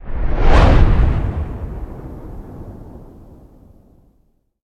mefistotel_blowout.ogg